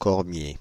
Ääntäminen
Synonyymit cornouiller mâle sorbier domestique Ääntäminen France (Île-de-France): IPA: /kɔʁ.mje/ Haettu sana löytyi näillä lähdekielillä: ranska Käännös Substantiivit 1.